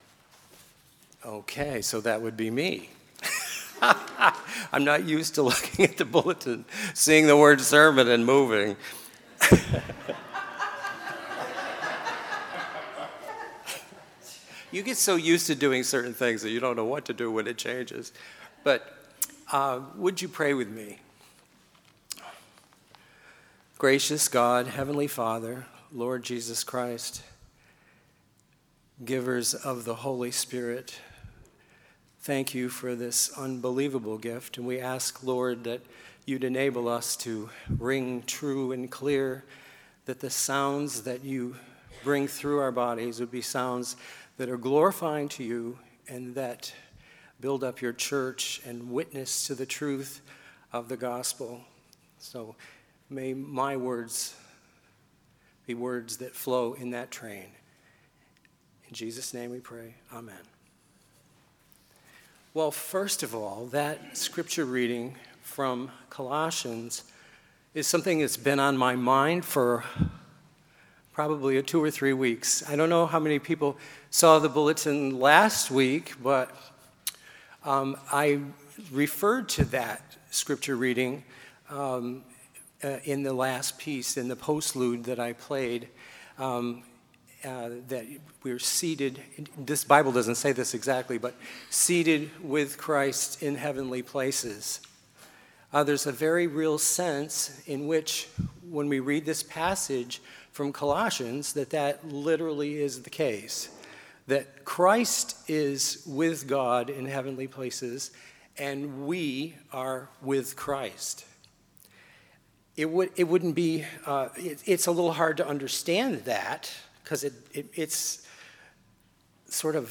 Sermon May 20, 2018 – Union Congregational Church of Groton